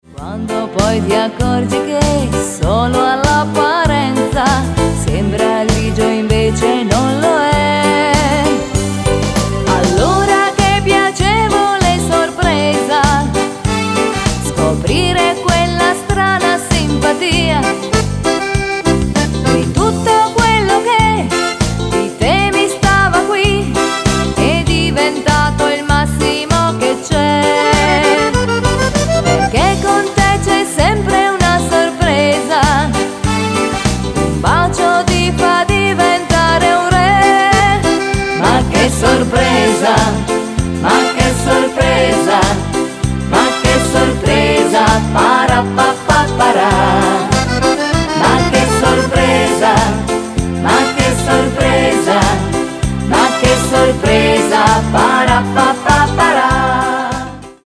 RITMO ALLEGRO  (3.49)